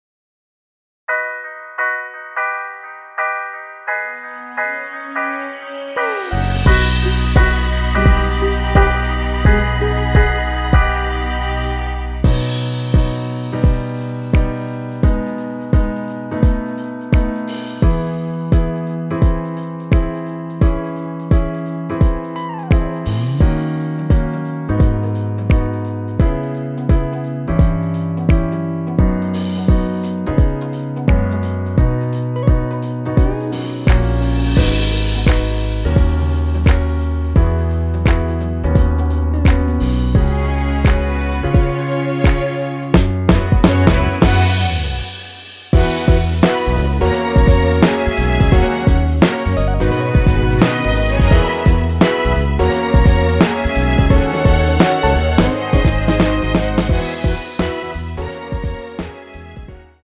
엔딩이 페이드 아웃이라서 노래하기 편하게 엔딩을 만들어 놓았으니 코러스 MR 미리듣기 확인하여주세요!
원키에서(+3)올린 MR입니다.
앞부분30초, 뒷부분30초씩 편집해서 올려 드리고 있습니다.